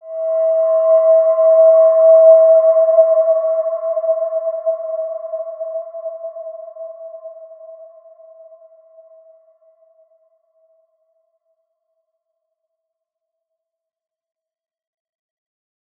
Wide-Dimension-E4-p.wav